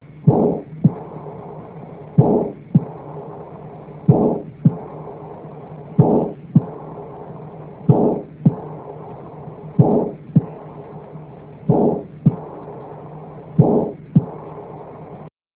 This Page contains some of the sounds of pathological Mitral and Aortic Valve Lesions.
AI and Long DM and SM and Attenuated S2